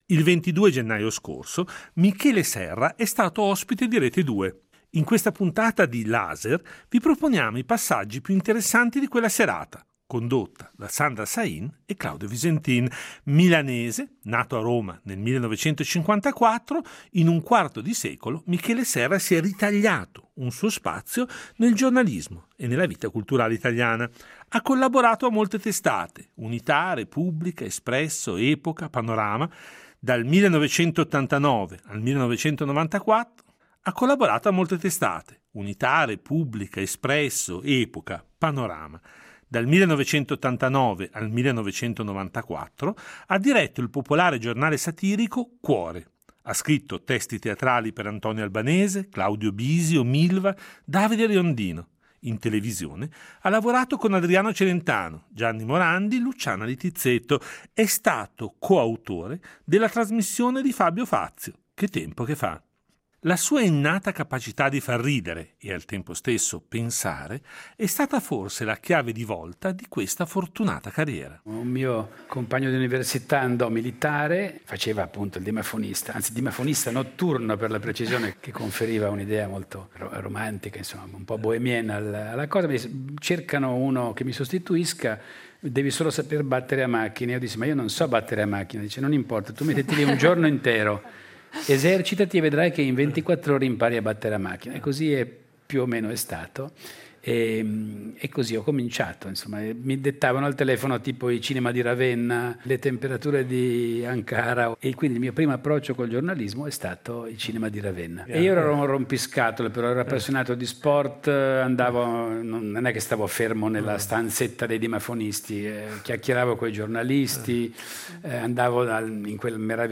Questo “Laser” raccoglie e ripropone i passaggi più interessanti del recente incontro pubblico con Michele Serra negli studi RSI del 22 gennaio 2020.